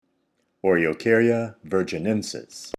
Pronunciation/Pronunciación:
O-re-o-cár-ya  vir-gi-nén-sis